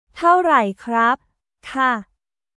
タオ ライ クラップ／カ?